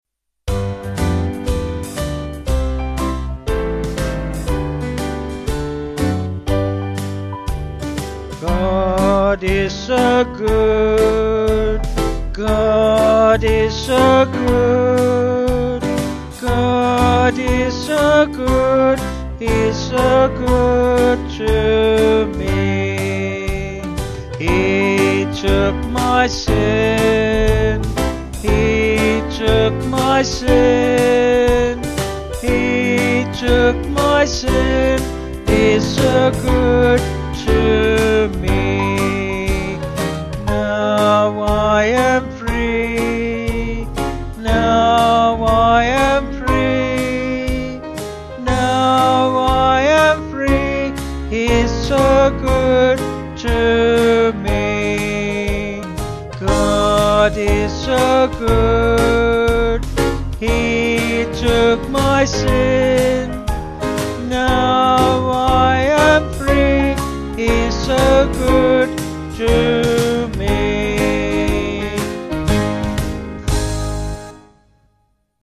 Vocals and Band   231.5kb Sung Lyrics